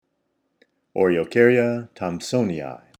Pronunciation/Pronunciación:
O-re-o-cár-ya thomp-sòn-i-i